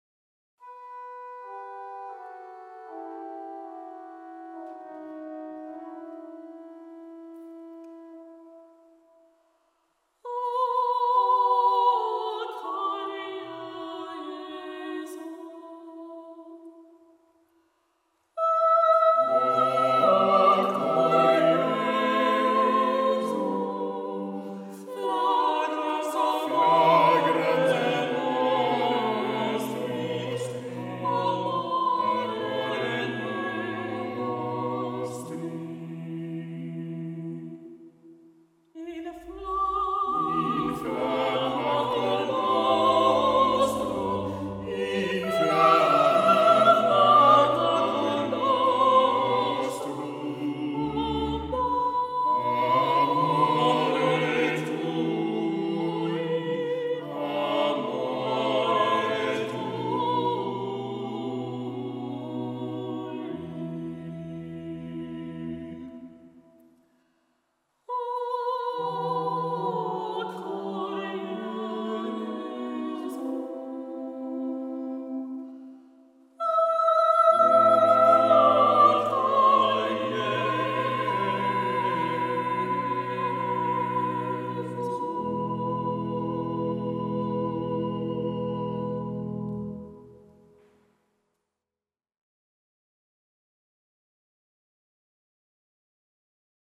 2018: St. Joseph Church, Needham
Choir: